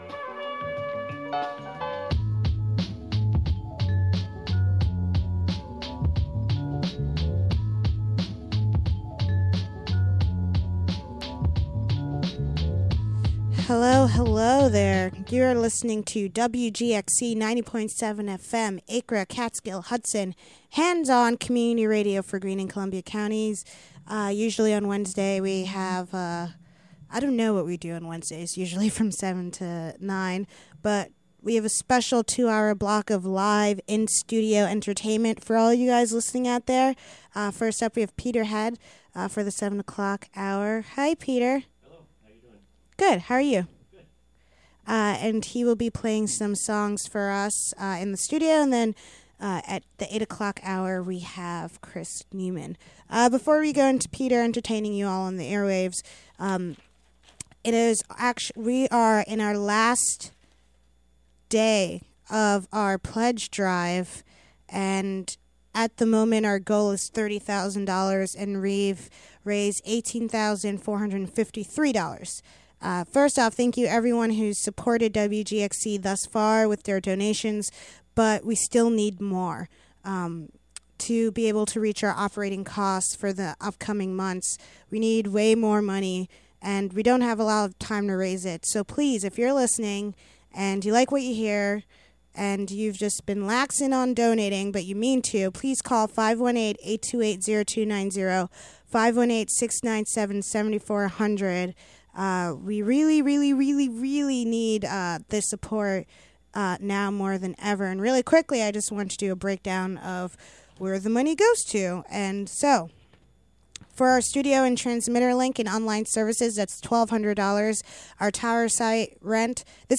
live performance.
studio during spring pledge drive.